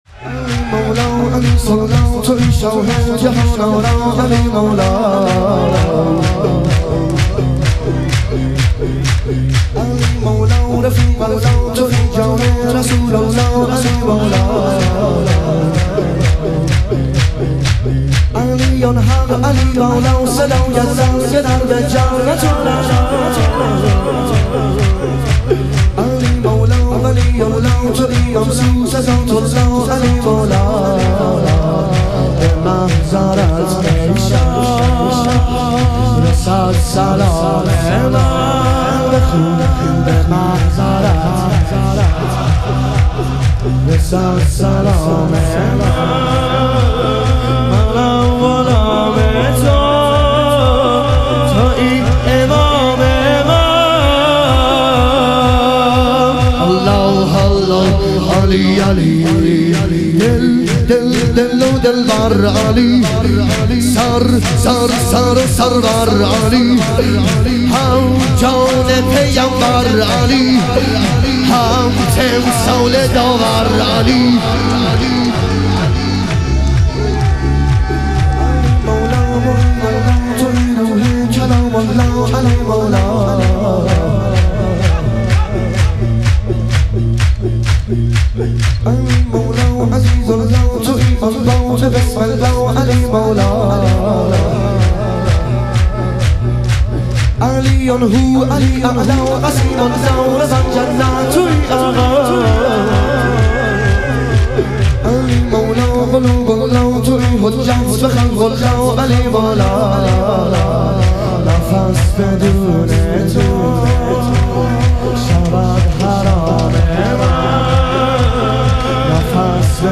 شور
شب شهادت امام صادق علیه السلام